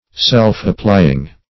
Self-applying \Self`-ap*ply"ing\, a.
self-applying.mp3